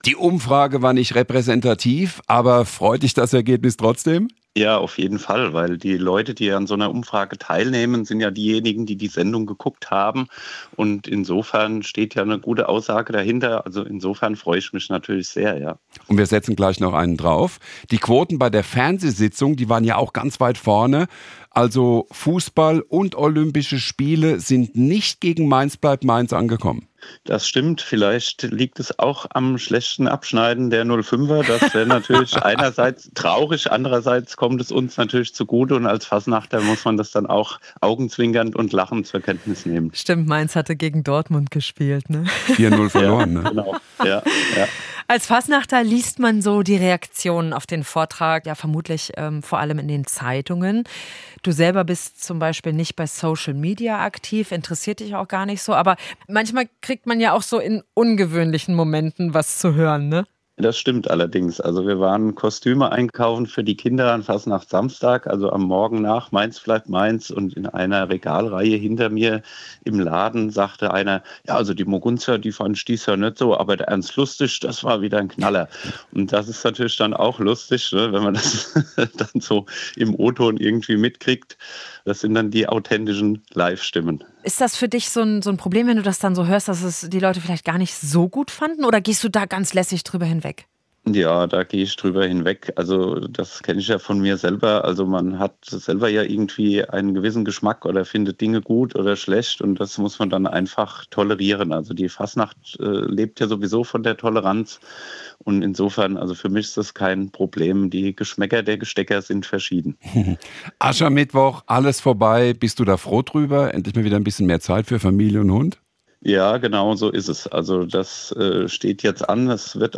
Wir haben mit dem beliebten Darsteller darüber gesprochen.